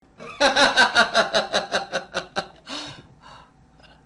ha ha ha ha ha
Tags: Soundboard angry gamer